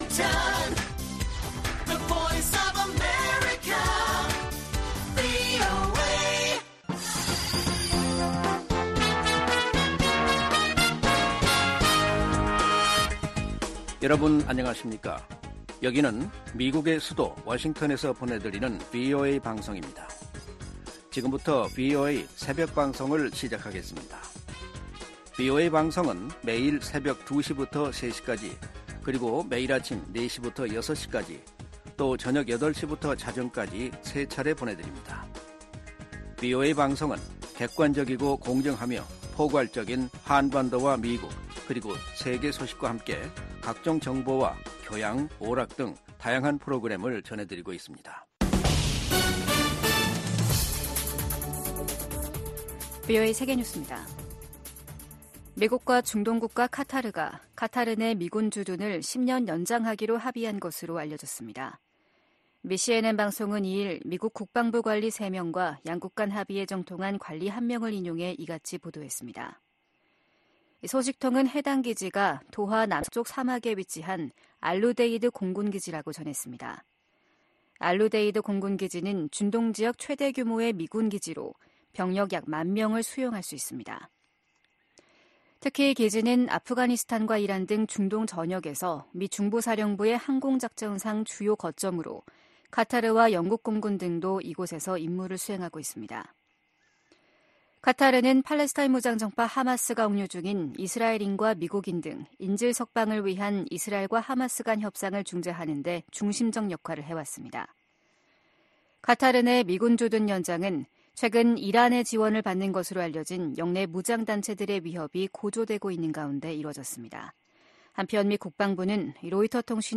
VOA 한국어 '출발 뉴스 쇼', 2024년 1월 4일 방송입니다.